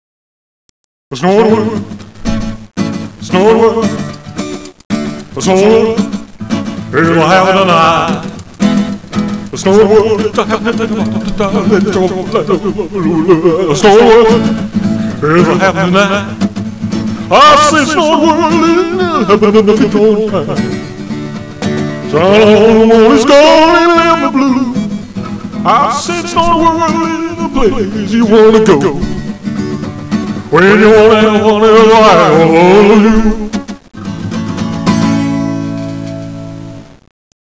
Sings